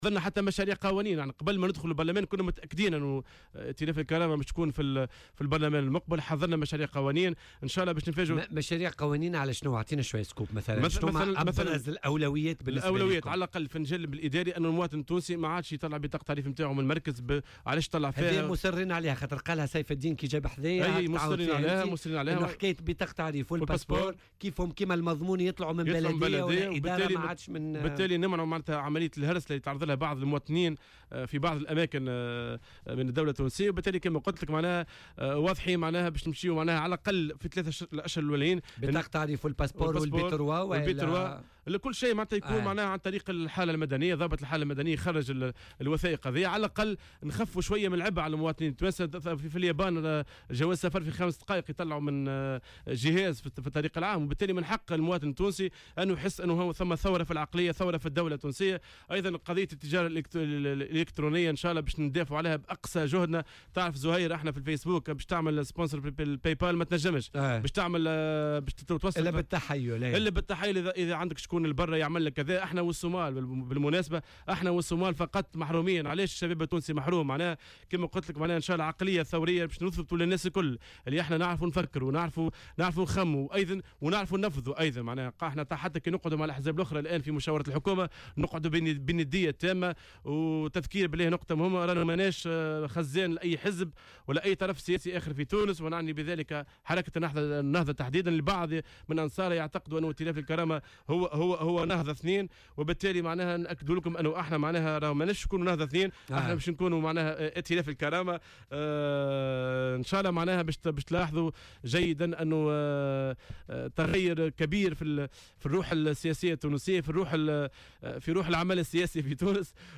وأضاف في مداخلة له اليوم في برنامج "بوليتيكا" على "الجوهرة أف أم" أنهم أعدوا مشاريع قوانين، سيسعون لتمريرها، من ذلك إحداث إدارة مدنية لاستخراج بطاقات التعريف الوطنية وجوازات السفر عوضا عن وزارة الداخلية، إضافة إلى فتح المجال أمام التجارة الالكترونية.